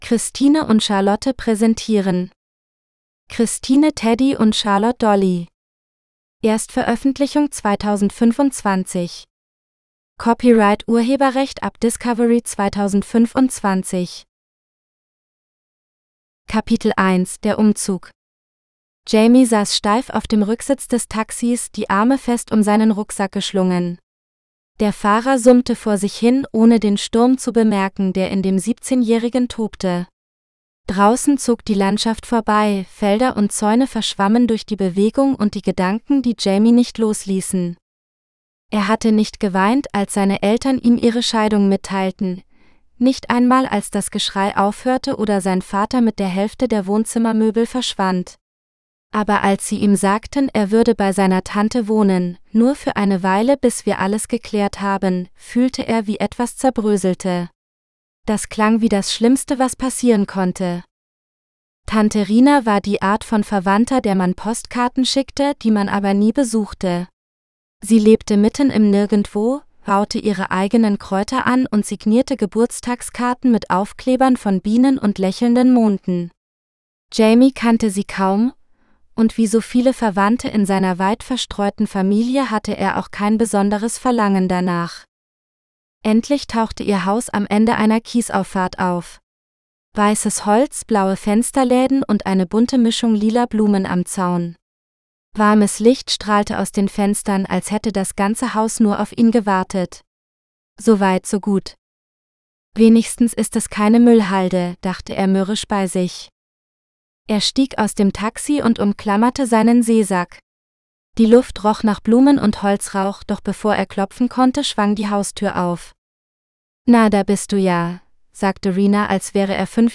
Christine and Charlotte Present… GERMAN (AUDIOBOOK – female): $US11.90